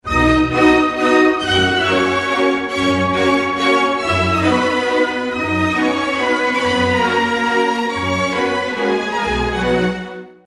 opera